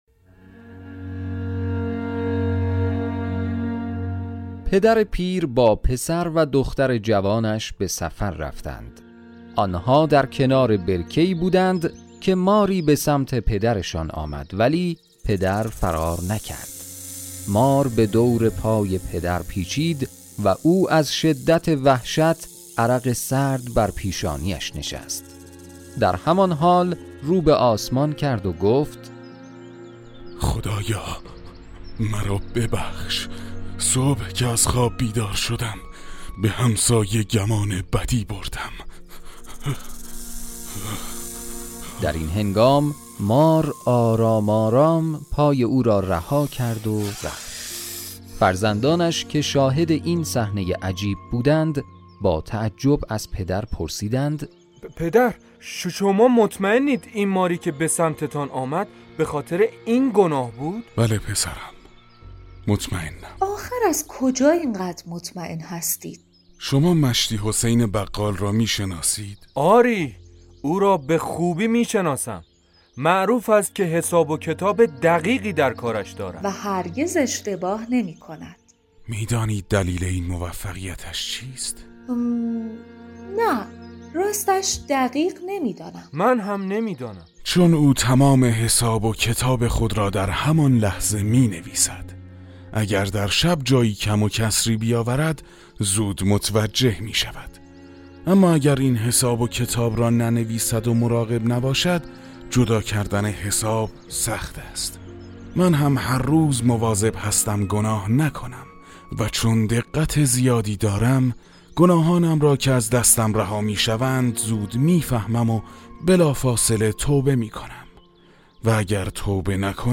علت حمله ی مار - داستان صوتی کوتاه 141 مکافات عمل - میقات مدیا
صداپیشگان :